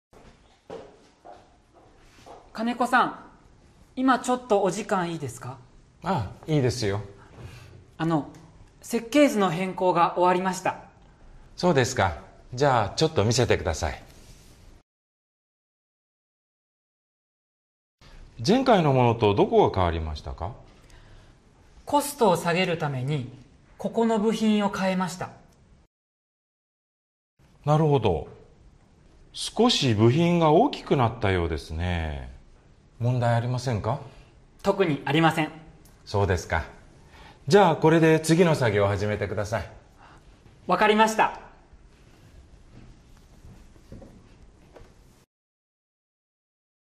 Conversation Transcript